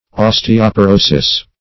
Osteoporosis \Os`te*o*po*ro"sis\, n. [NL.; osteo- + Gr. po`ros